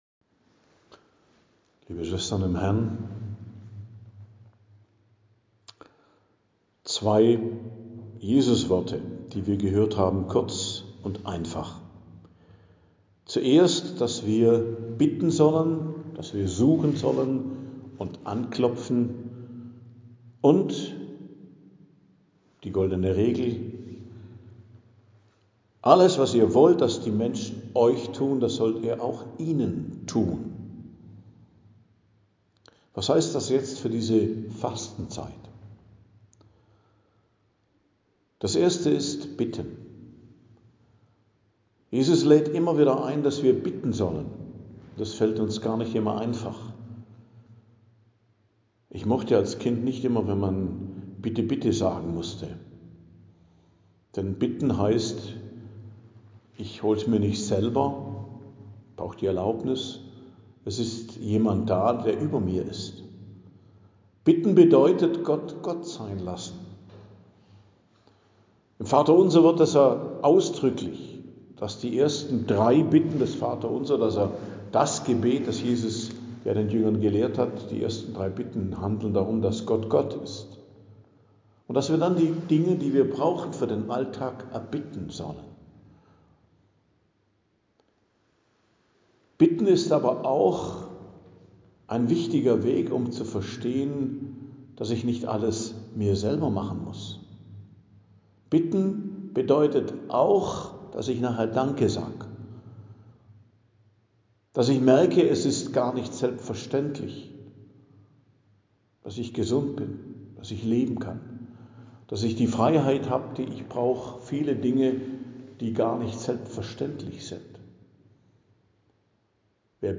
Predigt am Donnerstag der 1. Woche der Fastenzeit, 26.03.2026